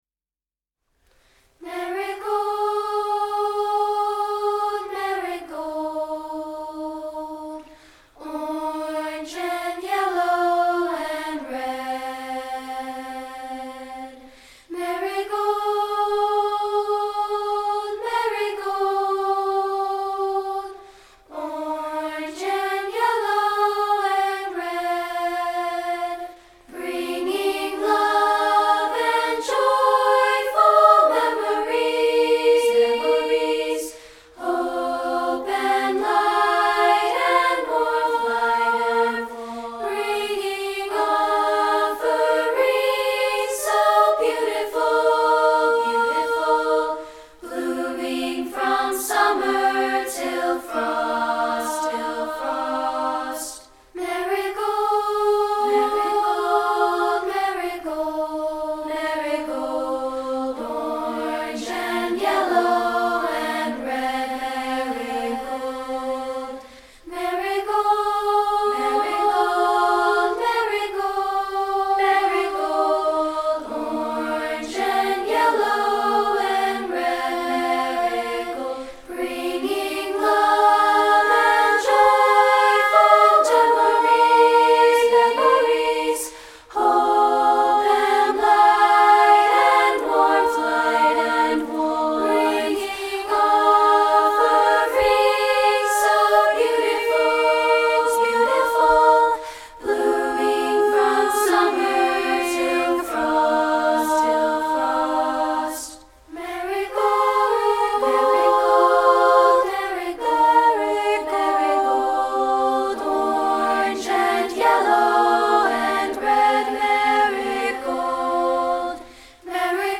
including this a cappella track.